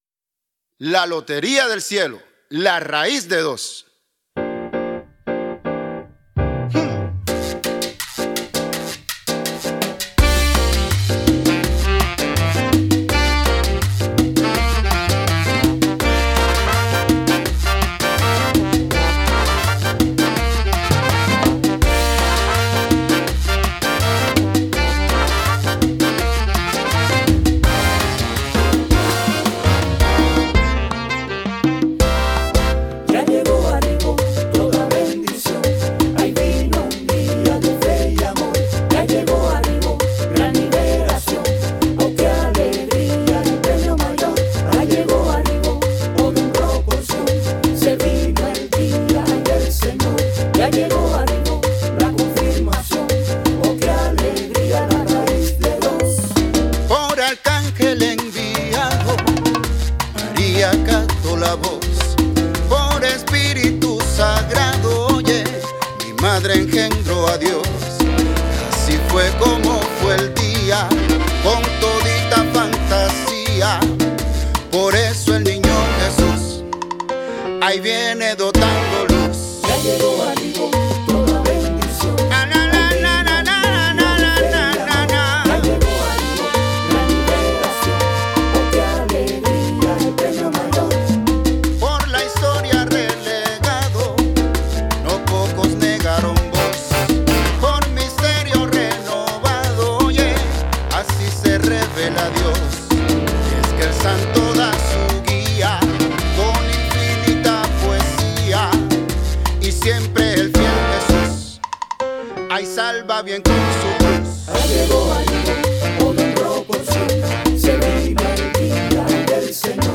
la alegre canción navideña cuyo género no es acorde con los villancicos tiernos típicamente interpretados para el Niño Jesús